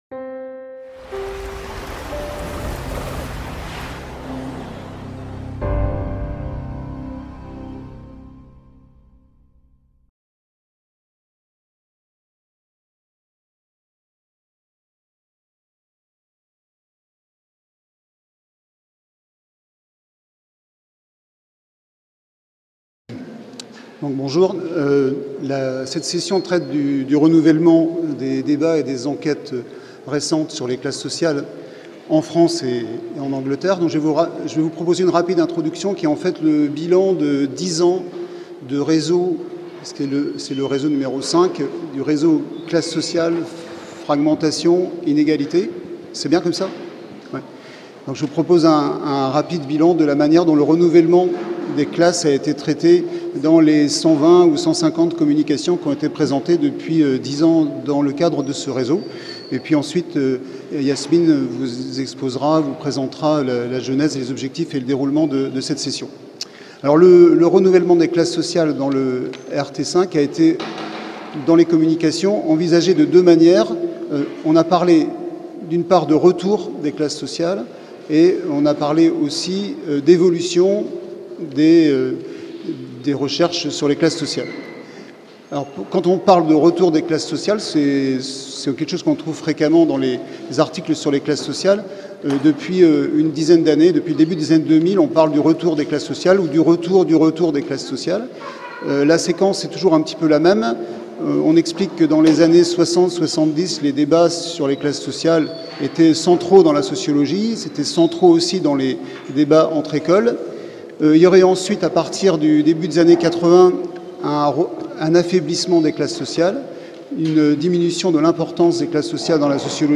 semi-plénière